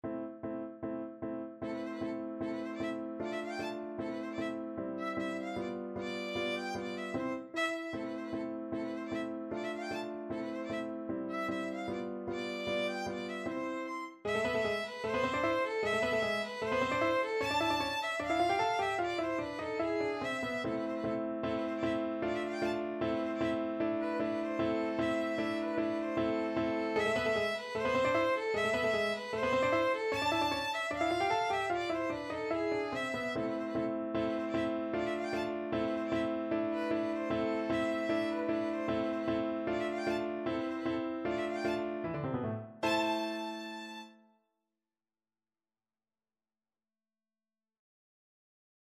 Violin
2/4 (View more 2/4 Music)
A minor (Sounding Pitch) (View more A minor Music for Violin )
Allegro scherzando (=152) (View more music marked Allegro)
Classical (View more Classical Violin Music)
burgmuller_arabesque_op100_2_VLN.mp3